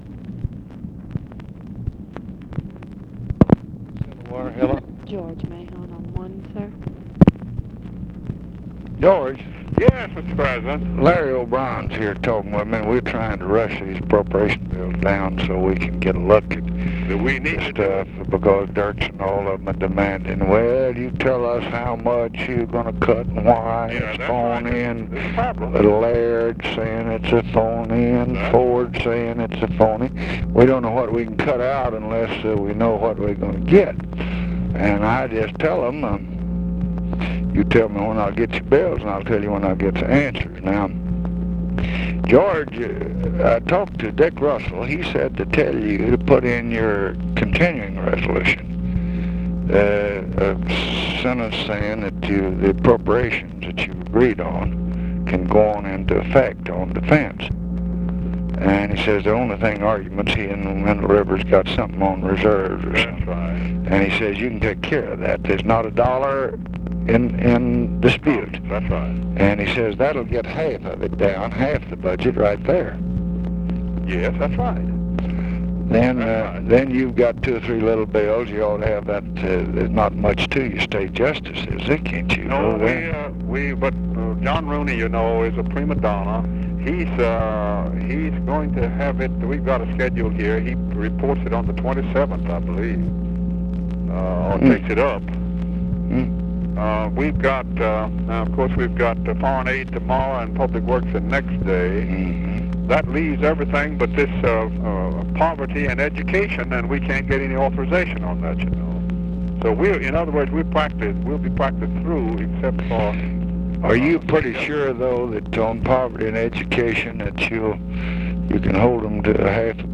Conversation with GEORGE MAHON, September 19, 1966
Secret White House Tapes